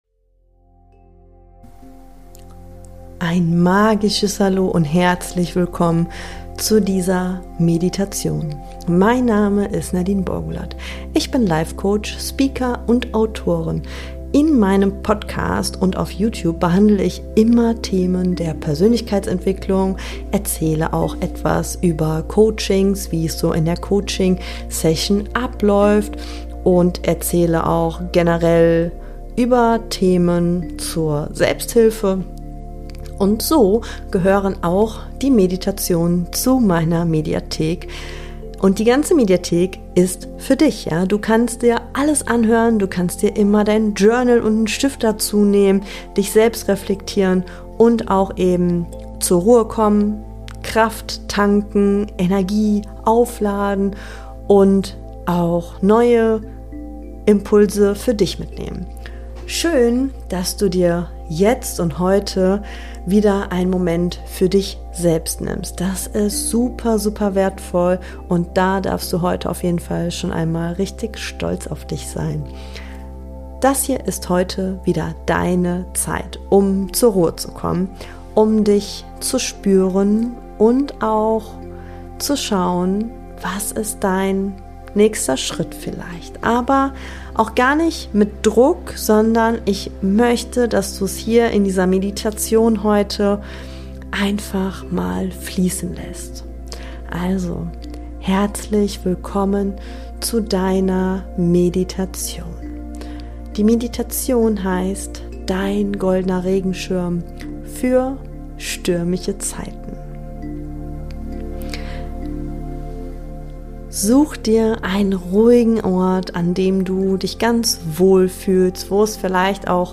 Geführte Meditation - dein goldener Regenschirm | Bali Inspiration ~ Boost your Mind to bright your Life Podcast